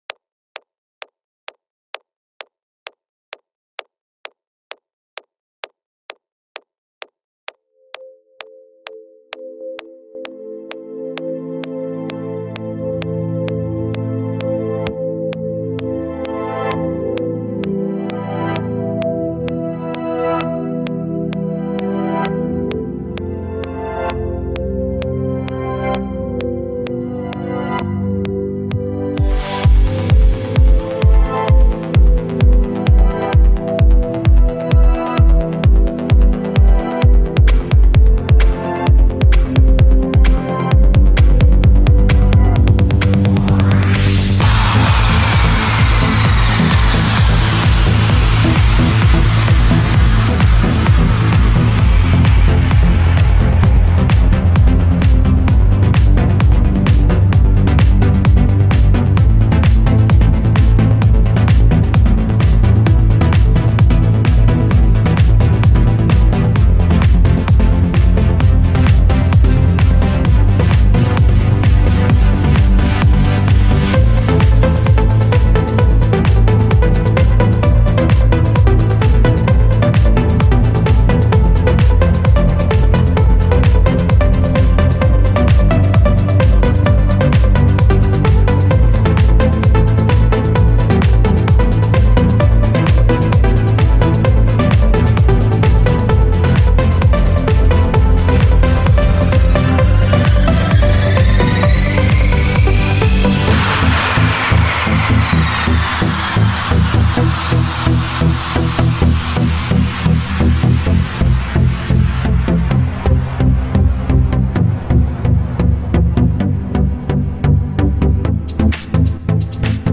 Vocals come a bit later.
Progressive Trance